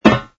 fs_ml_steel01.wav